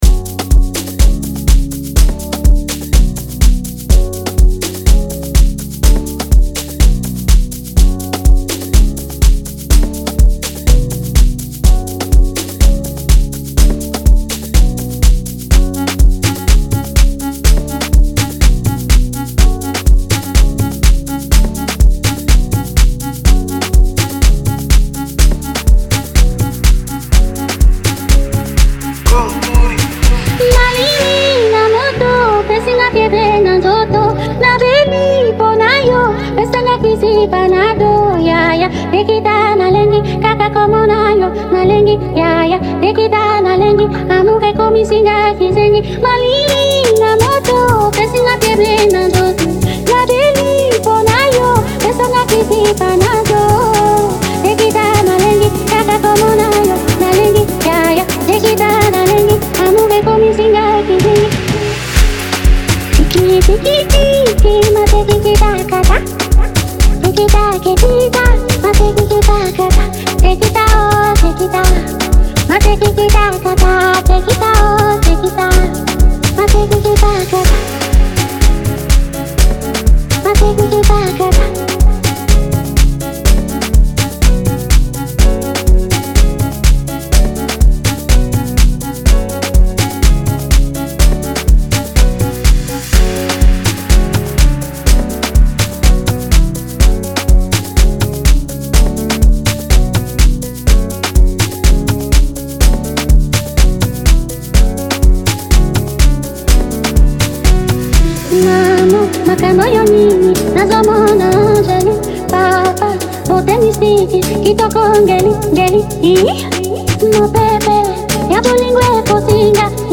danceable tune
well-produced Afrobeats track